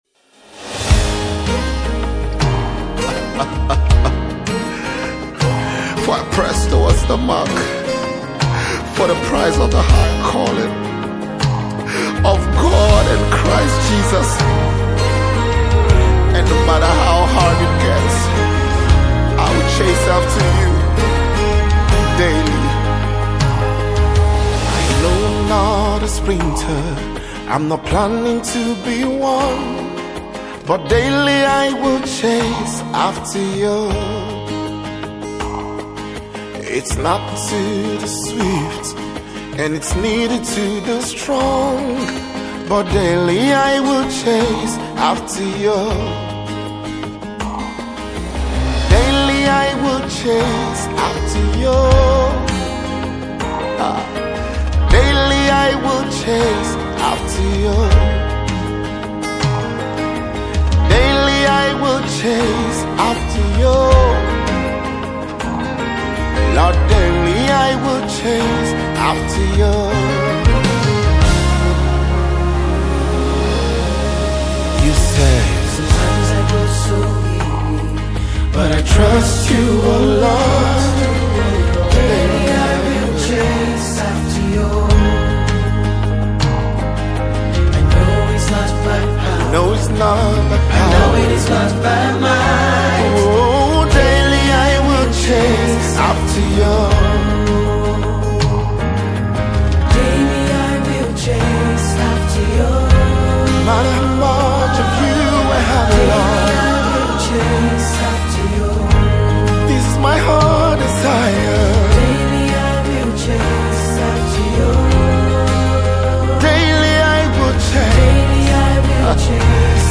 Gospel Songs